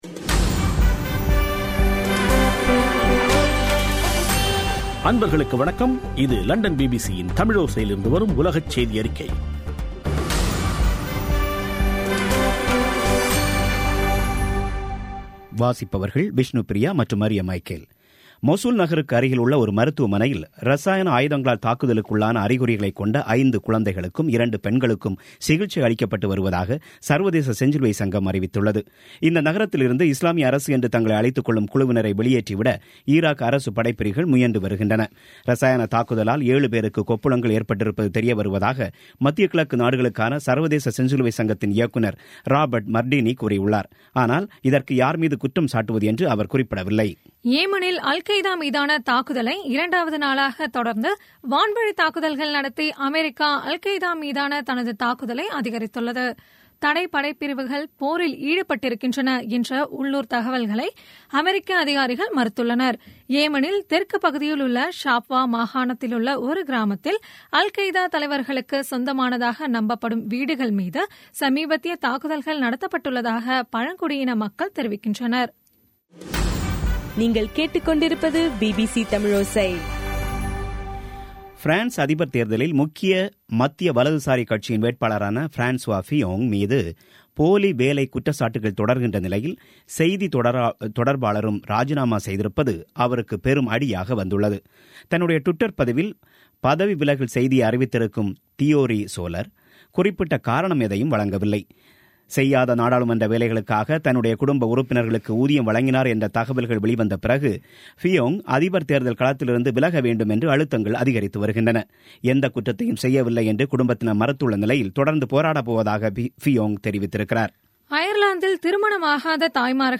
பிபிசி தமிழோசை செய்தியறிக்கை (03/03/2017)